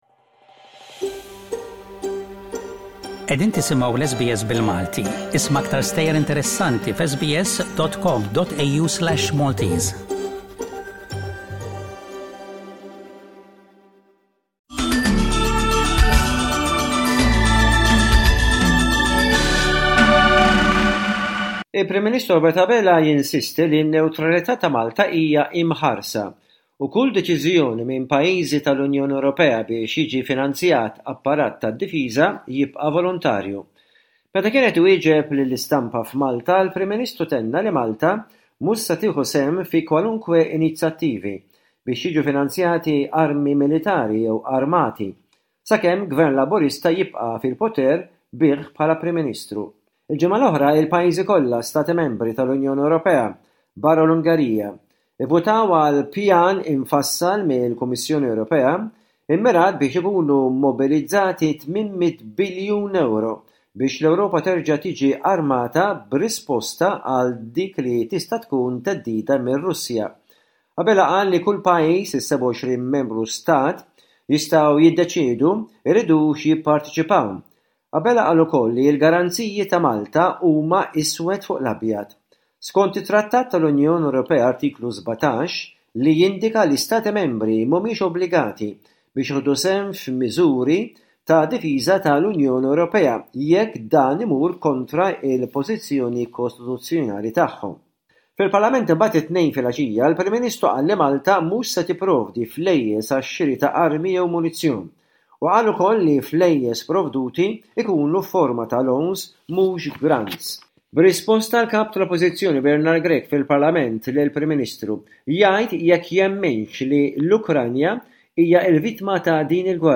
News from Malta